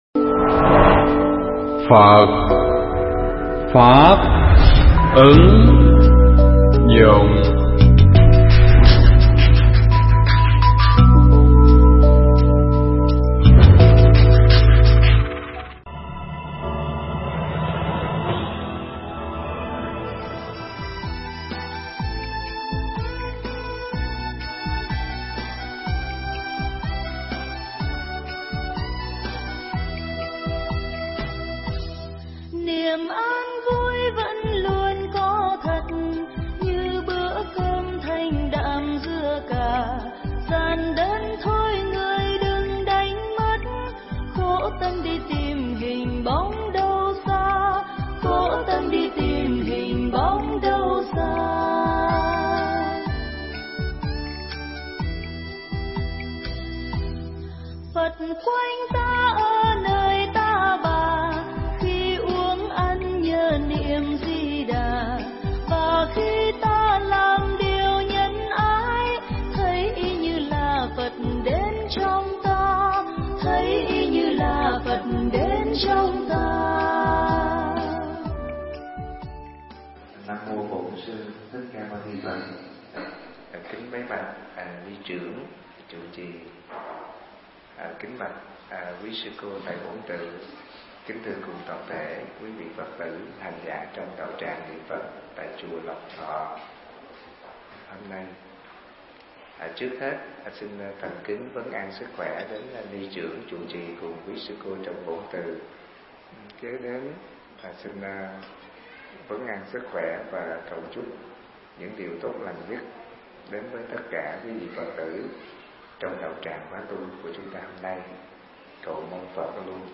Nghe Mp3 thuyết pháp Ý Nghĩa Của Việc Đi Chùa Phóng Sanh
Nghe mp3 pháp thoại Ý Nghĩa Của Việc Đi Chùa Phóng Sanh